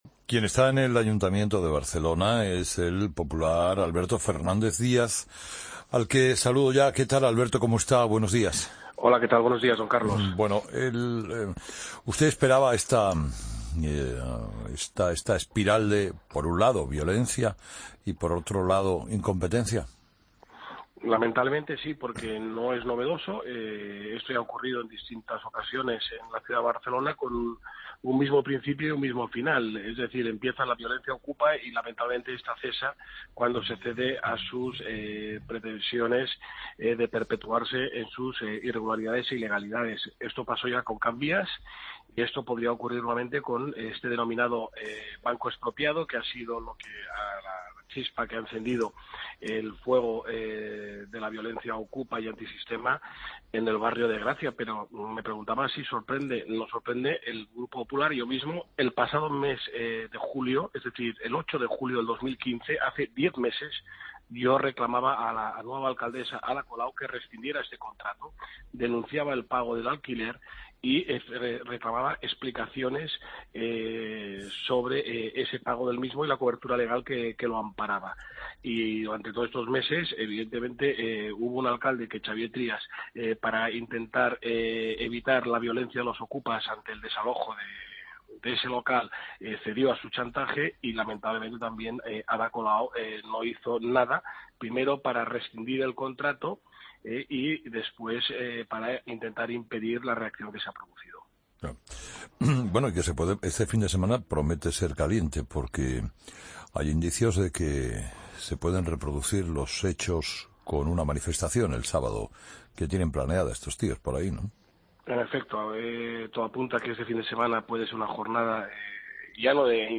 Alberto Fernández Díaz, concejal del PP en el Ayuntamiento de Barcelona: "Mientras Colau es amiga de los antisistema, es hostil con emprendedores y turismo"